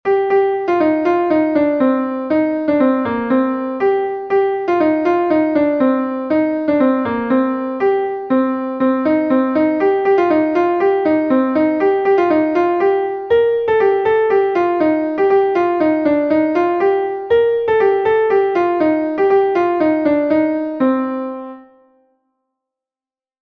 Julig Ar Ververo is a Bale from Brittany